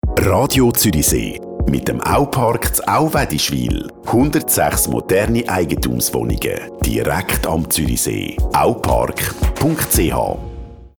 Pre-Roll (Aupark) – 10 Sekunden